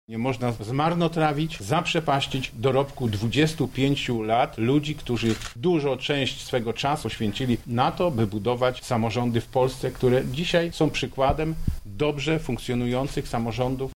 Inicjatywę podsumowuje Marszałek województwa lubelskiego Sławomir Sosnowki